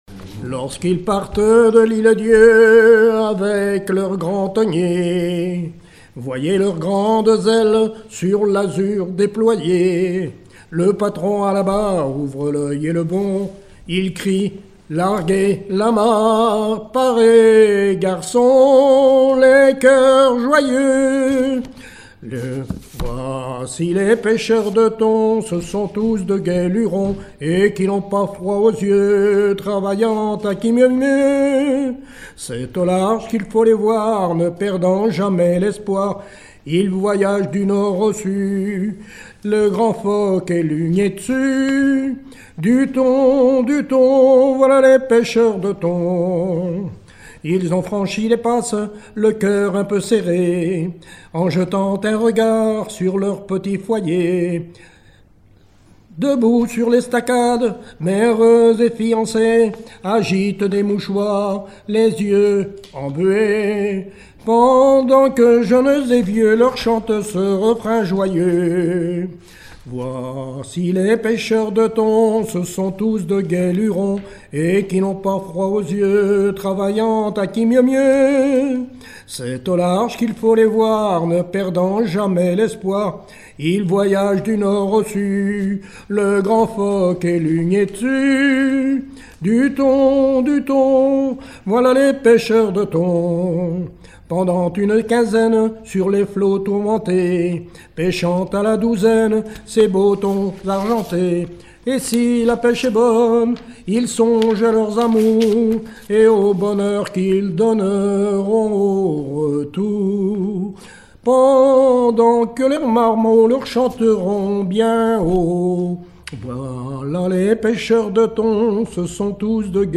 répertoire de chansons traditionnelles
Pièce musicale inédite